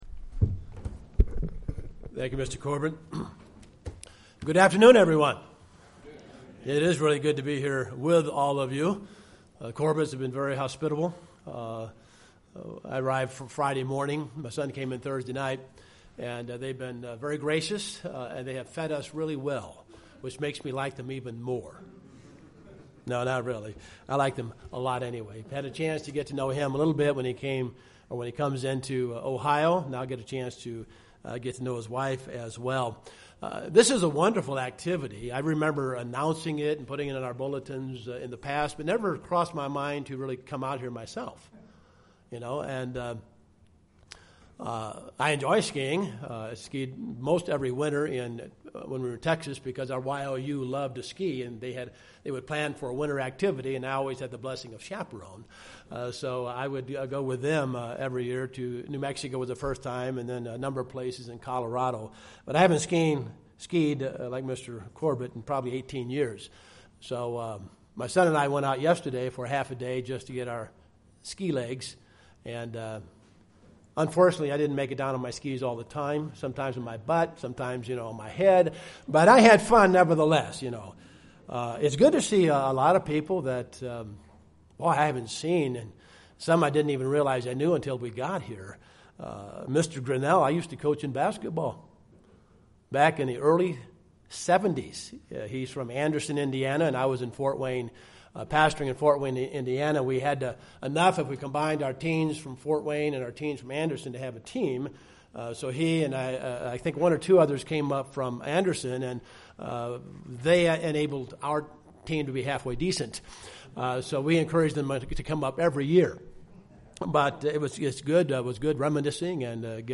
Given in Las Vegas, NV
UCG Sermon Studying the bible?